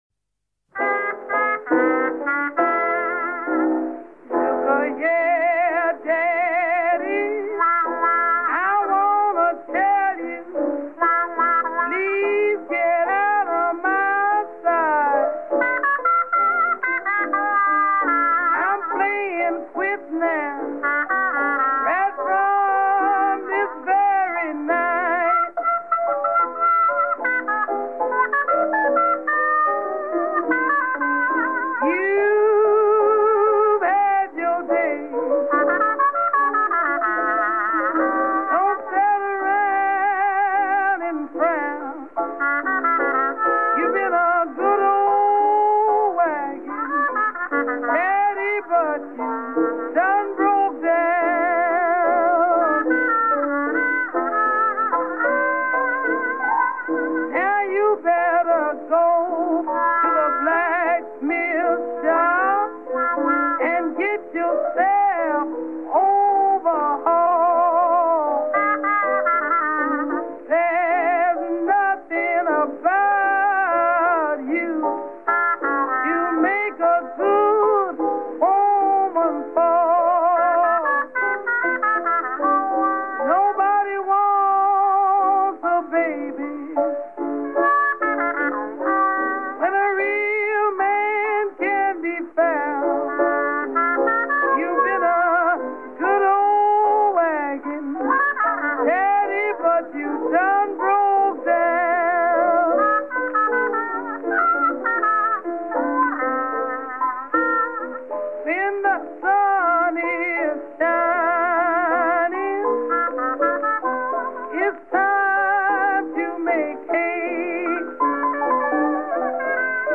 Джаз (закрыта)